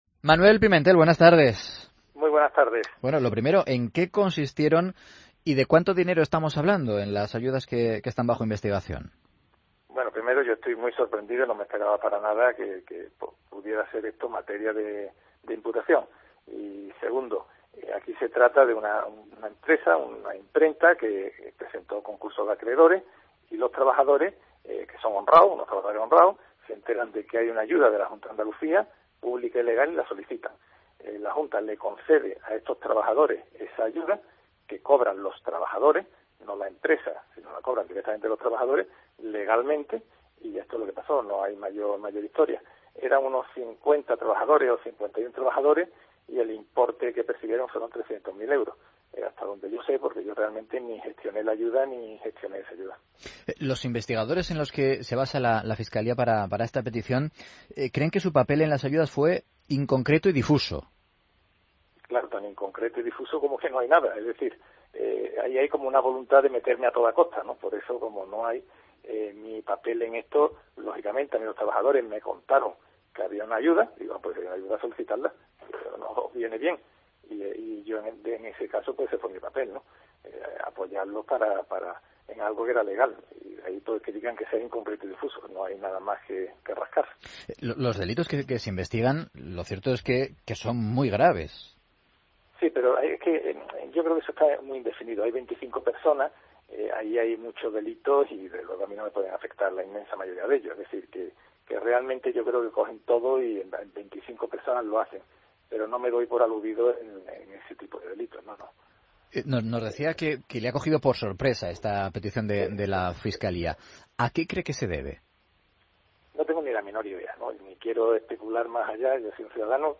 AUDIO: Escucha la entrevista a Manuel Pimentel en Mediodía COPE